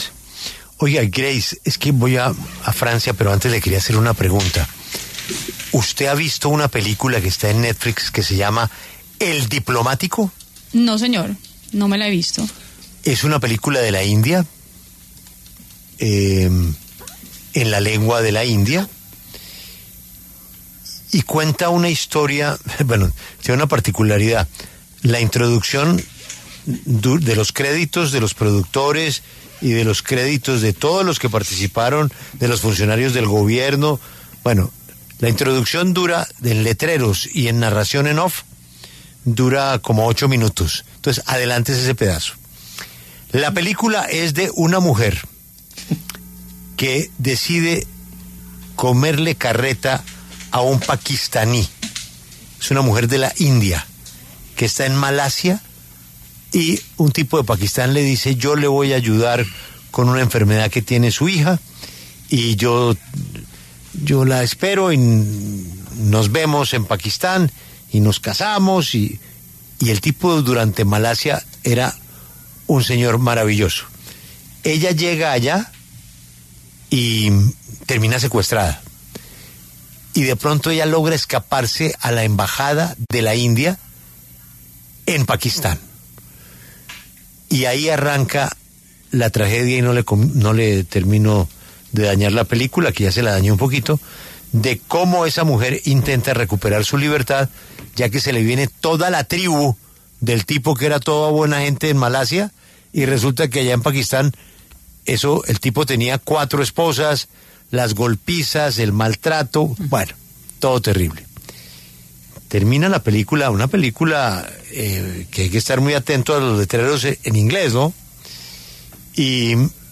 Huppert pasó por los micrófonos de La W y detalló su experiencia al leer por primera vez el guion la película de esta mujer que todo el mundo conoce.